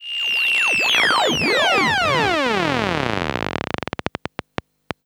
Uurgh.wav